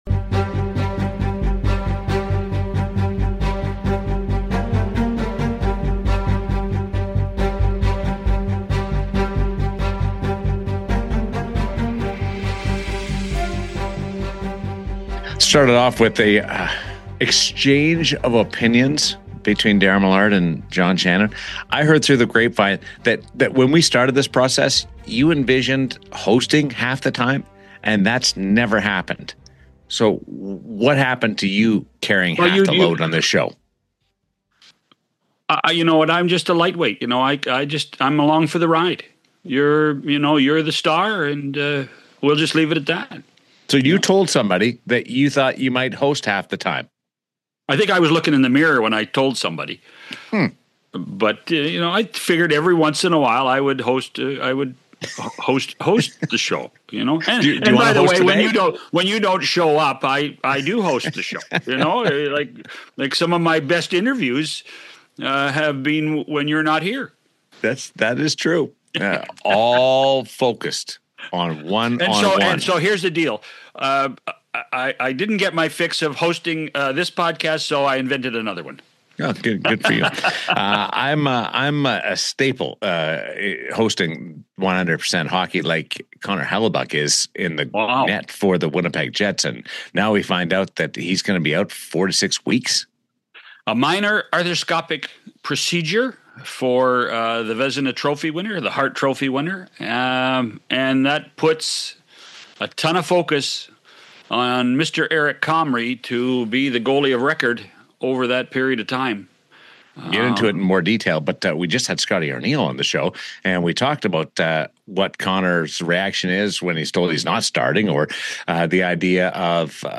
Pat Verbeek, General Manager of the Anaheim Ducks, joins John Shannon and Daren Millard on 100% Hockey to talk about his team’s surprising rise to first place early in the season.